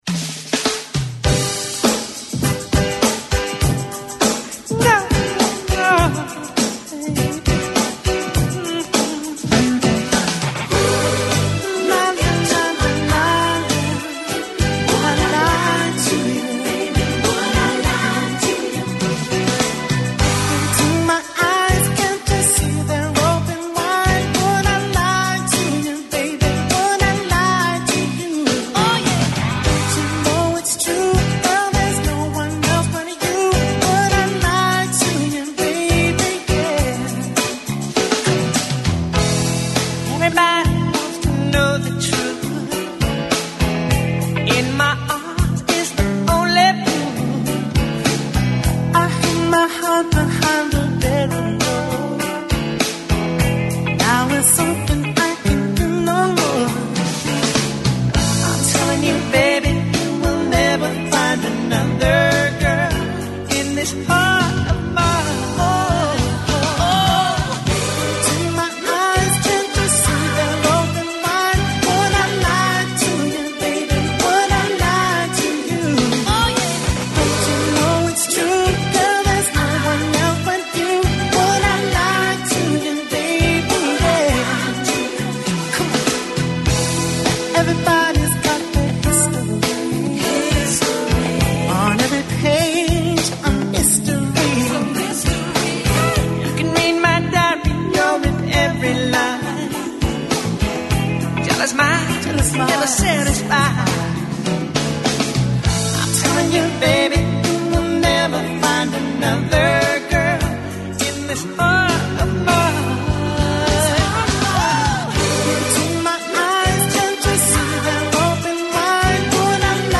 Ακούστε την εκπομπή του Νίκου Χατζηνικολάου στον ραδιοφωνικό σταθμό RealFm 97,8, την Πέμπτη 15 Μαΐου 2025.